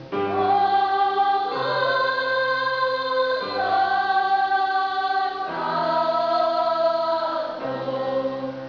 学級全員による合唱です。
聞かせどころだけを録音しました。
もとは体育館でステレオ録音したものです。
自然における神の栄光 ４部合唱 近藤朔風 ベートーベン あります